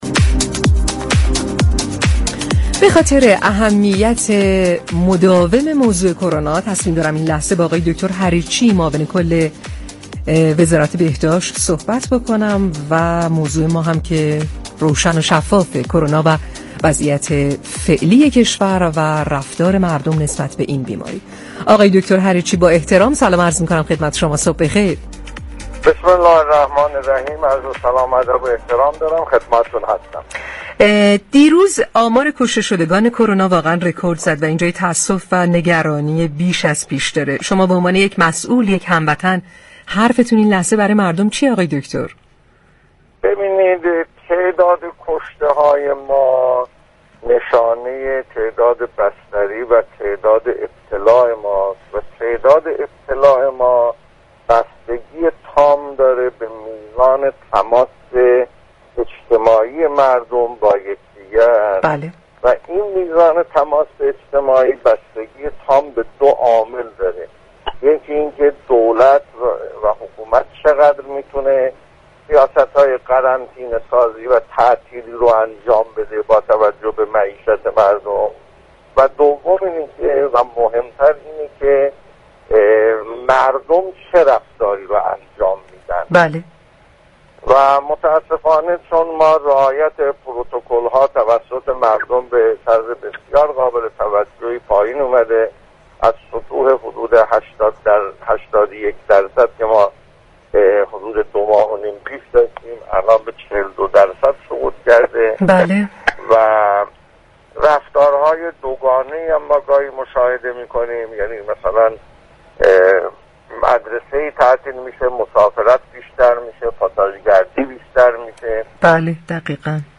ایرج حریرچی در گفتگو با برنامه تهران كلینیك رادیو تهران درباره ركورد فوتیهای كرونا در روز دوشنبه 21 مهرماه اظهار داشت: به طور كلی تعداد كشته ها ناشی از تعداد مبتلایان و بیماران بستری شده و بالطبع تعداد مبتلایان هم وابسته به تماس های اجتماعی است.